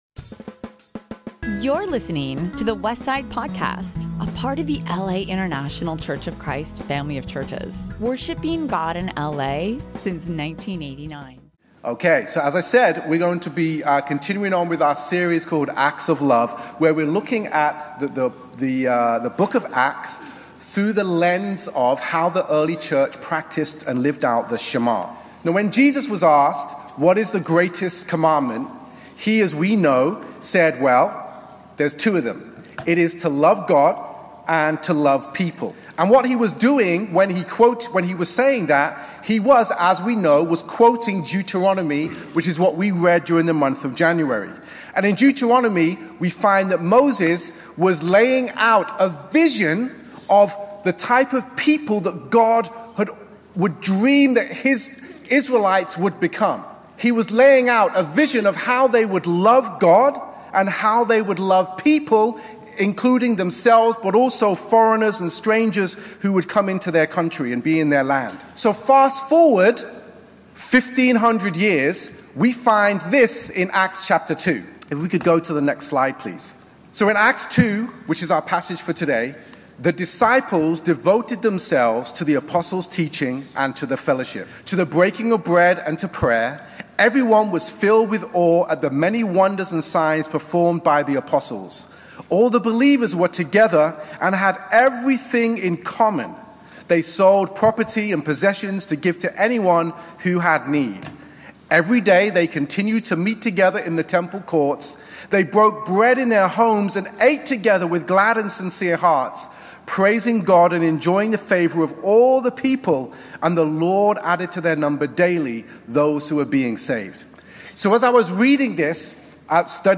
Past Sermons | The Westside Church - Los Angeles, CA